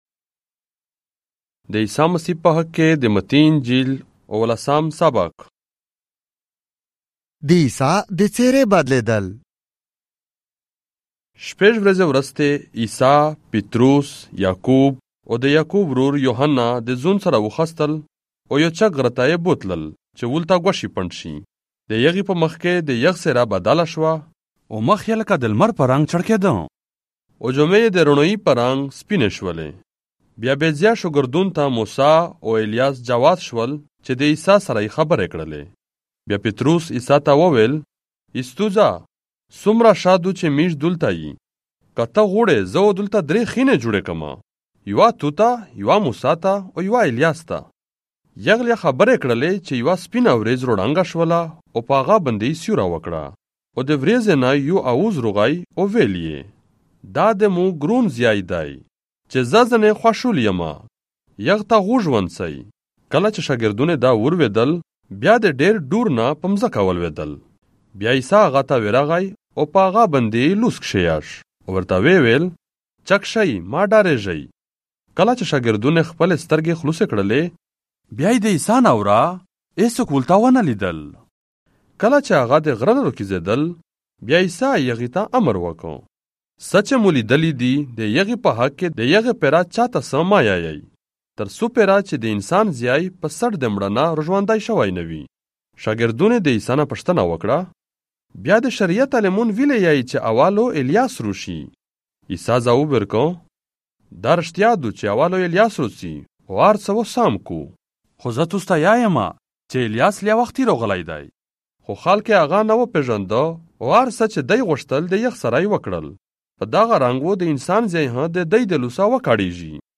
دې عيسیٰ مسيح په حق کې دې متي انجيل - اوولاسام ساباق، په پشتو ژبه، مرکزي (آډیو) ۲۰۲۵